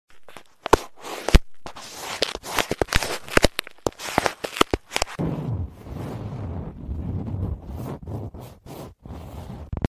ASMR Hand Triggers: The Subtle sound effects free download
ASMR Hand Triggers: The Subtle Sound of Fingers Gliding Over a Microphone